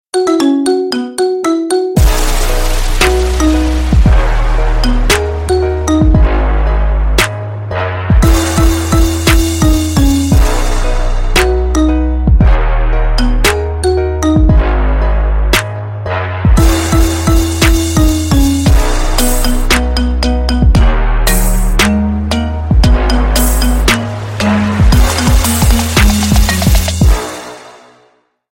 Рингтоны Без Слов
Рингтоны Ремиксы